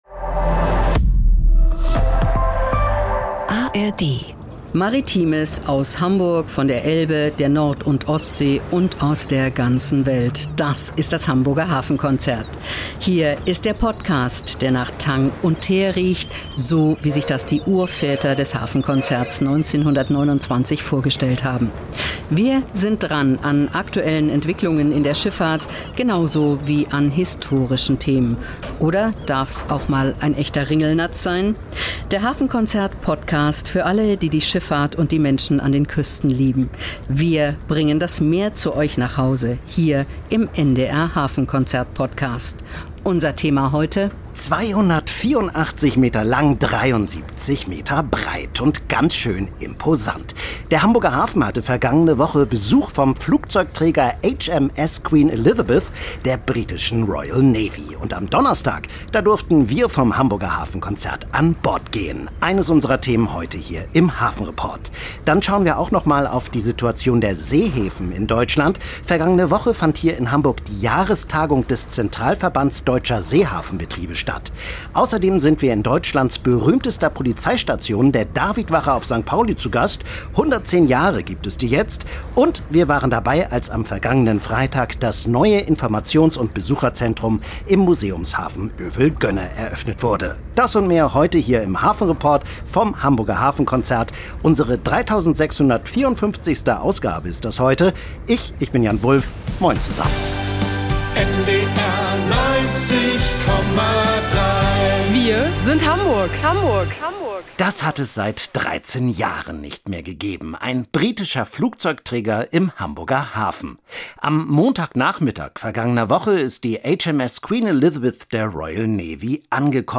Spannende Reportagen und exklusive Berichte rund um den Hamburger Hafen, die Schifffahrt und die norddeutsche Geschichte.